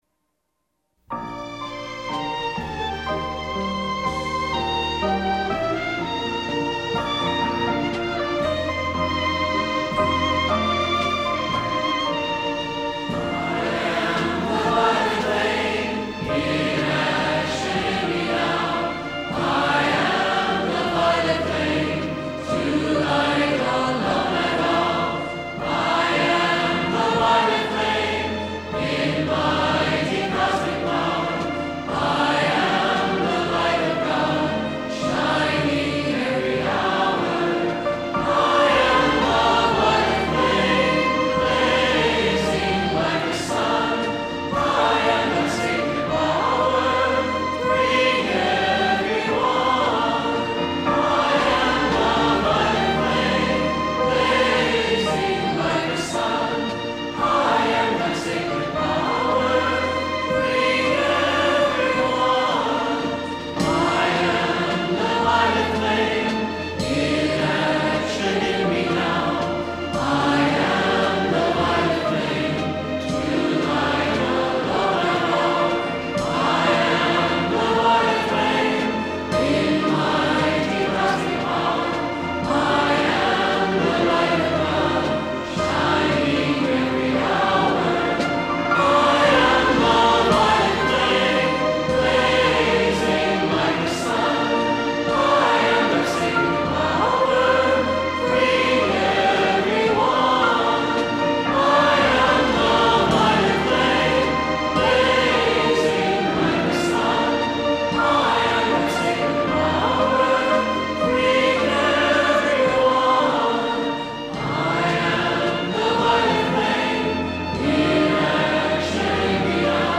11-I-AM-the-Violet-Flame-Sung-3x.mp3